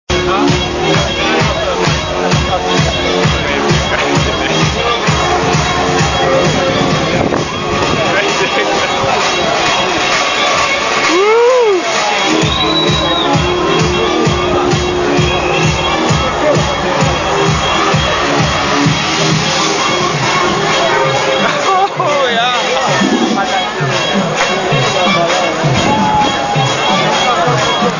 another song is over the top of it, nfi wat it is